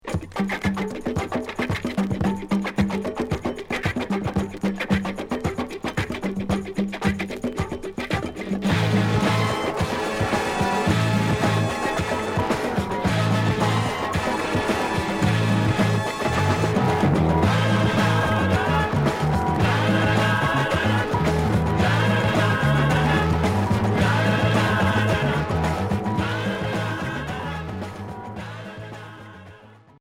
Groove pop Premier 45t